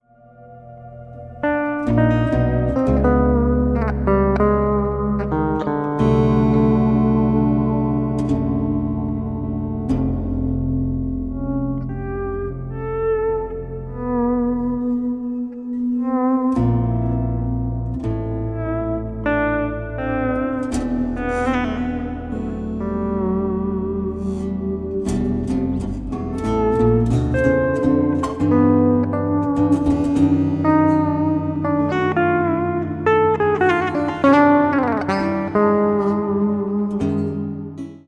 Electric guitar, Electric Baritone guitar